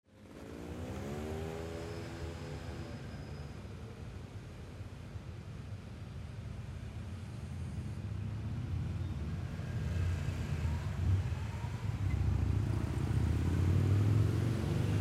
Background Sound Effects, Transportation Sound Effects
traffic_07-1-sample.mp3